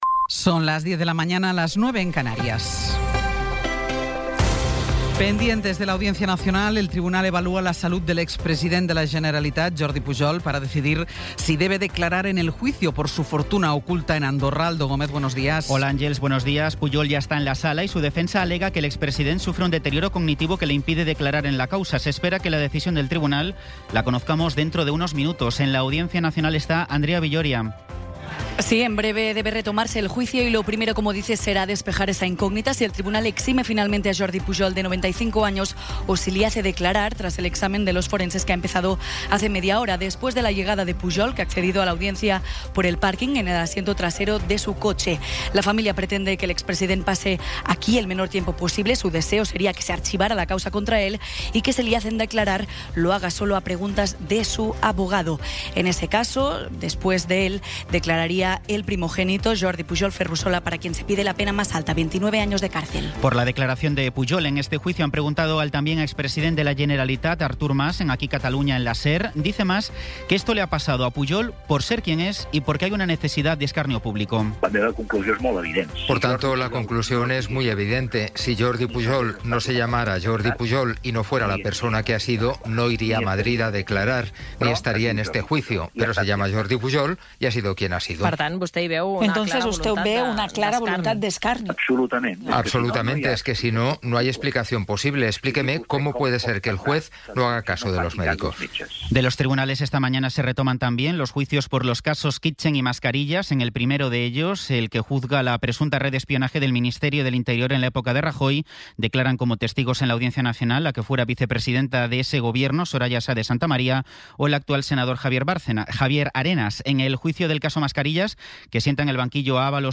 Resumen informativo con las noticias más destacadas del 27 de abril de 2026 a las diez de la mañana.